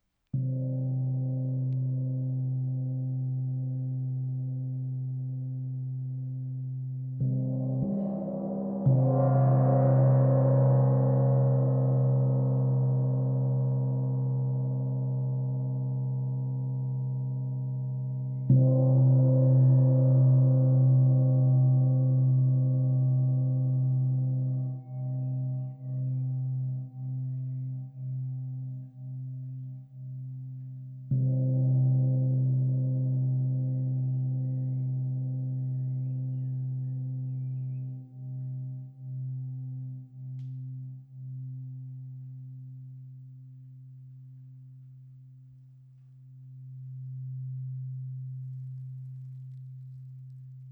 Wind Gong • 45 cm
Découvrez le Wind Gong, un instrument sonore qui émet des vibrations aériennes.
Grâce à sa taille de 45 cm, ce gong offre un équilibre parfait entre richesse harmonique et maniabilité.
• Sonorité riche et expansive, avec des harmoniques évolutives
WindGong.wav